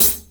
35CHH 01  -R.wav